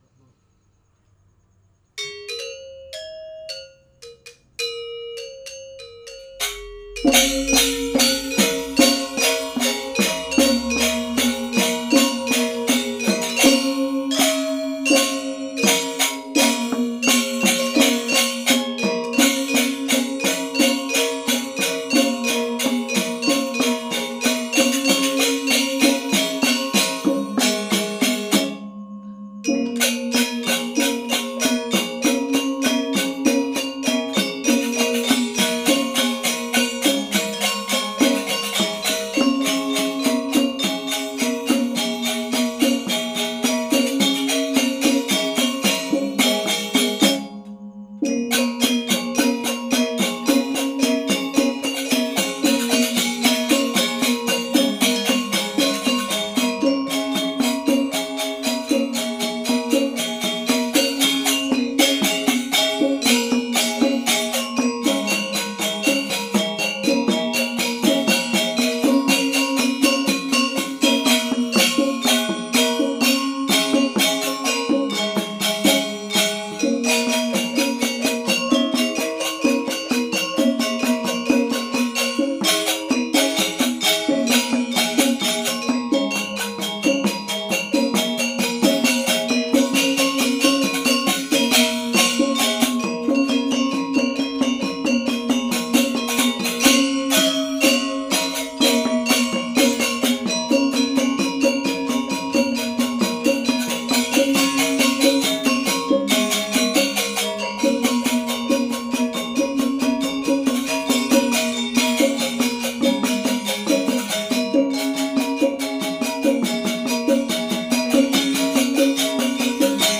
Balinese Gamelan Angklung Cremation Music